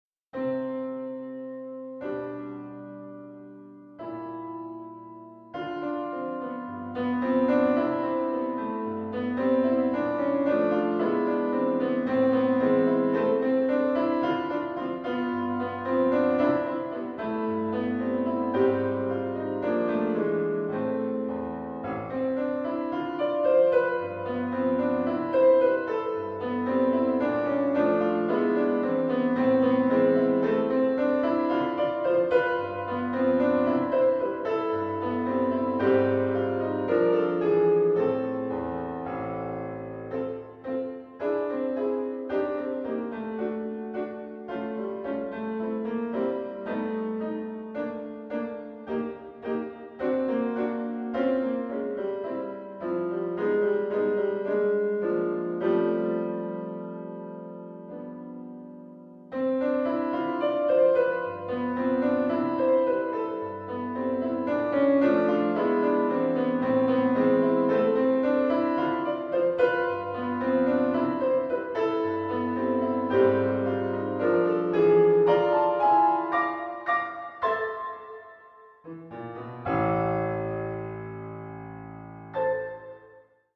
Genre: Solo Piano
Solo Piano